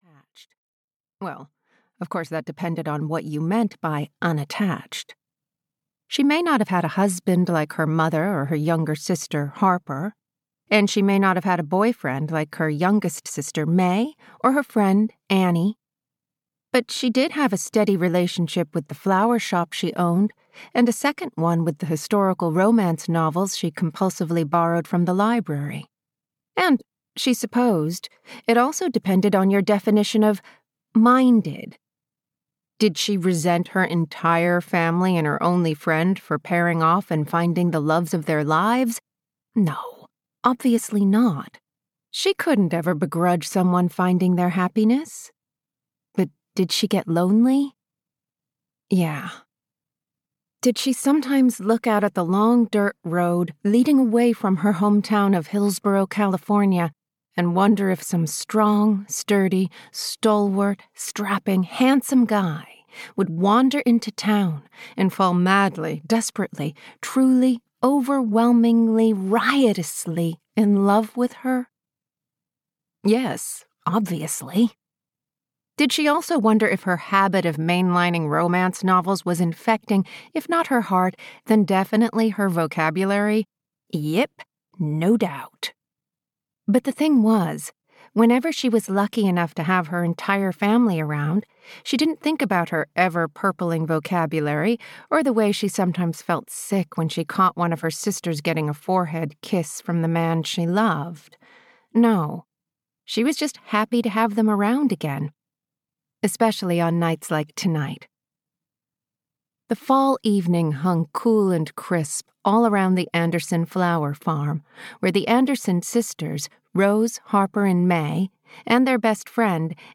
Audio knihaHome at Summer's End (EN)
Ukázka z knihy